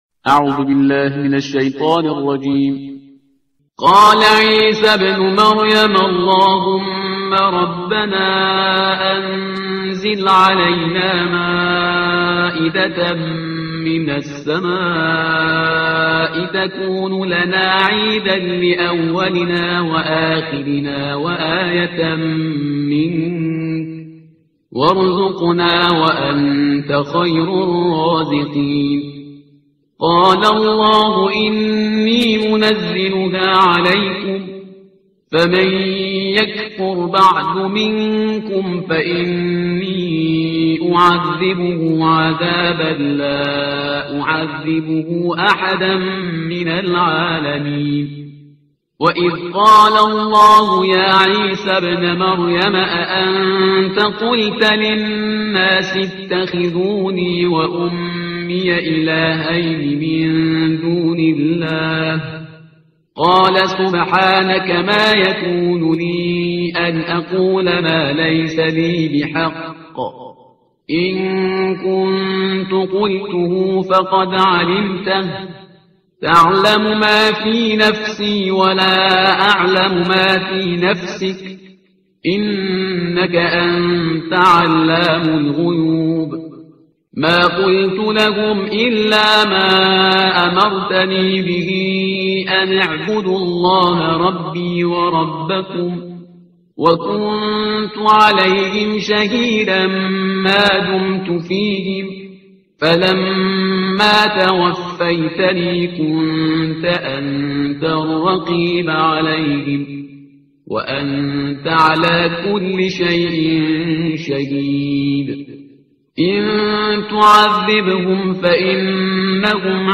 ترتیل صفحه 127 قرآن با صدای شهریار پرهیزگار